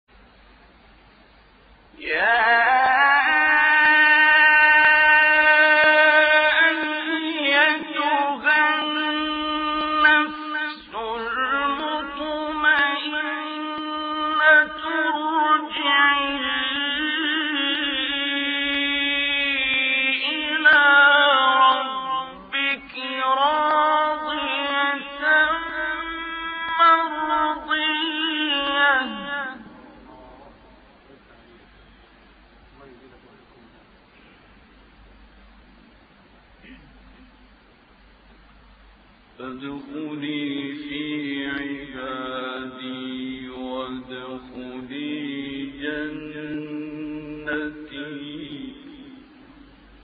طنین آیات پایانی سوره فجر با صدای محبوب‌ترین قاریان مصری